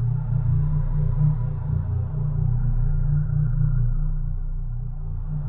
extractLoop.ogg